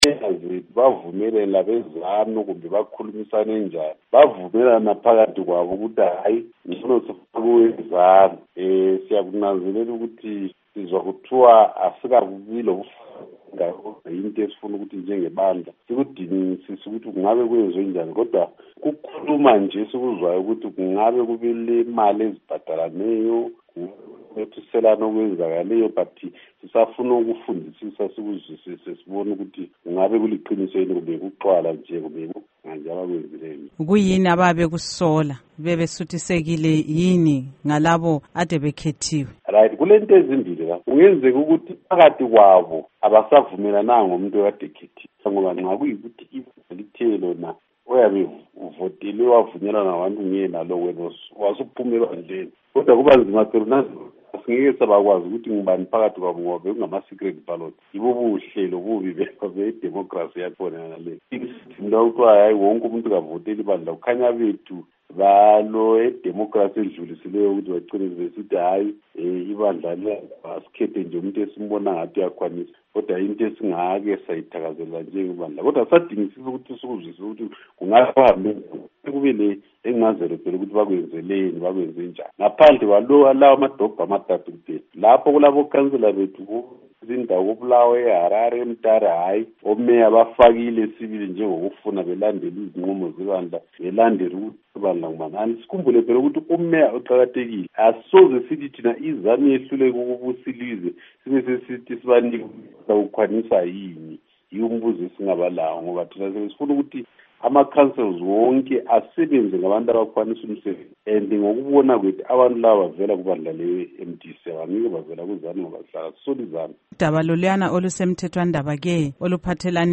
Ingxoxo LoMnu. Joel Gabhuza